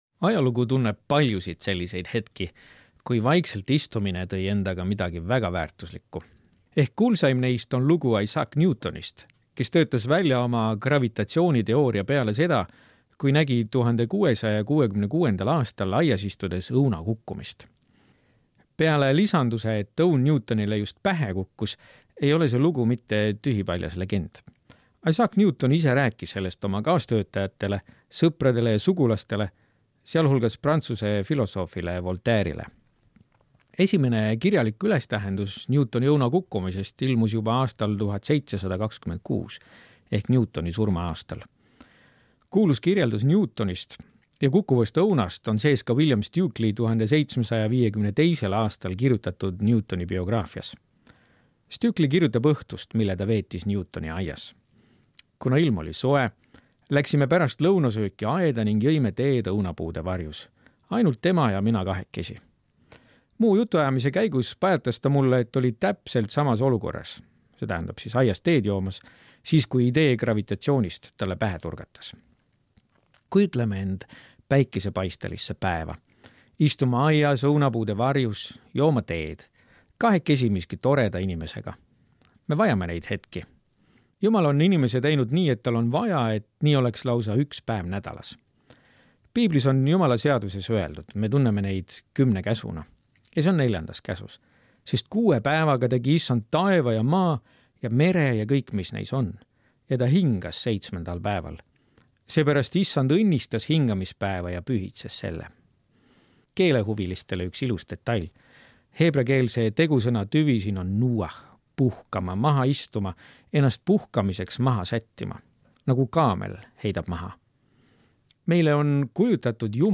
Hommikumõtisklused ERR-is